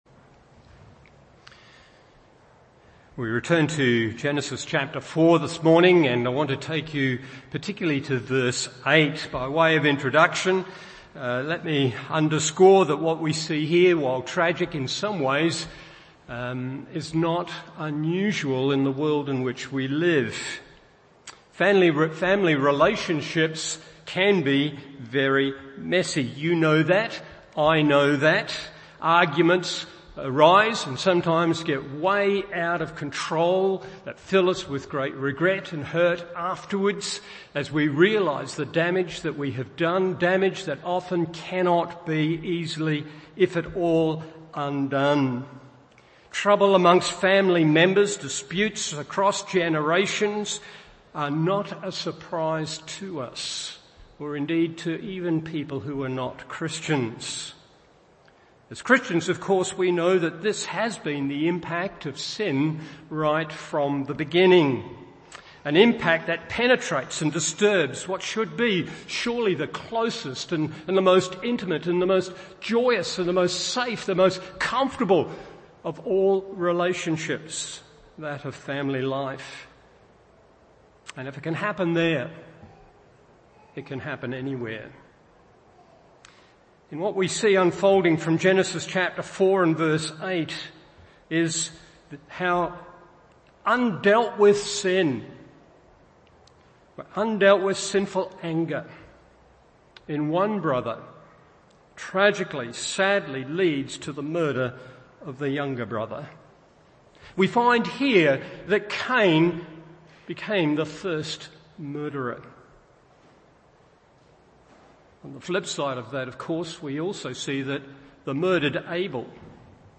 Morning Service Genesis 4:8-15 1. The Believer’s Death 2. The Believer’s Defense 3. The Believer’s Declaration…